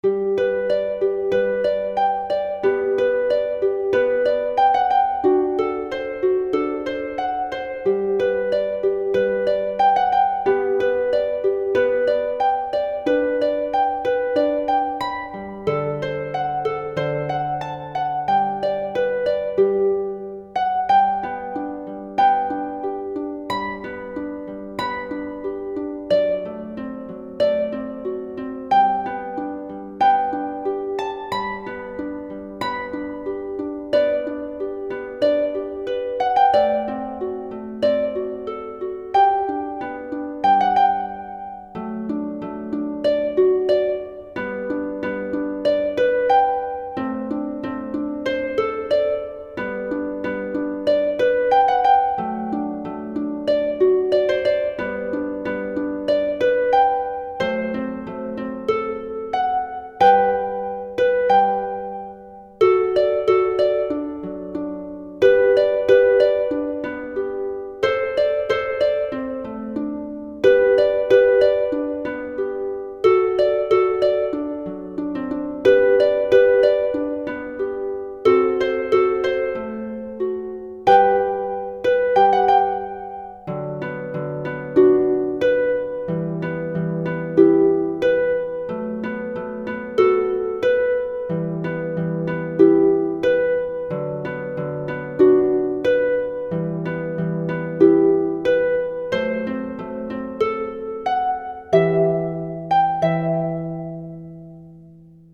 Sheet Music for Solo Harp
seven pieces for pedal or lever harp, beginning intermediate
midi generated mp3)